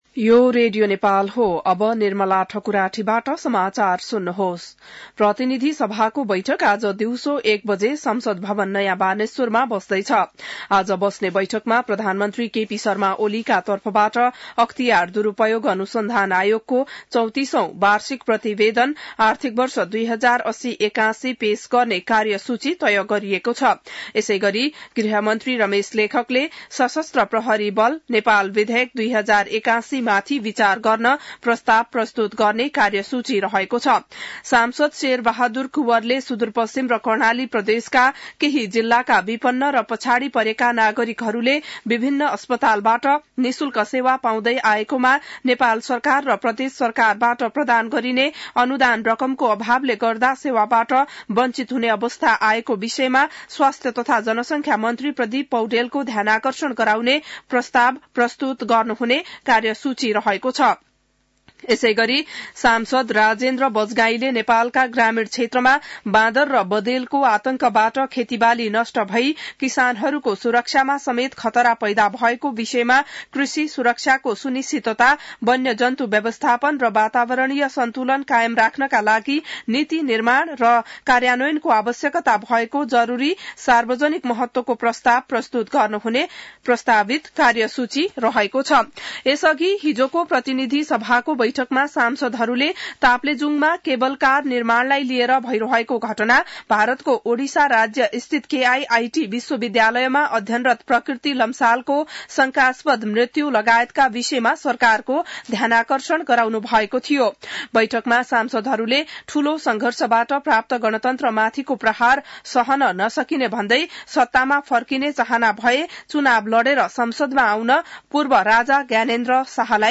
बिहान १० बजेको नेपाली समाचार : १३ फागुन , २०८१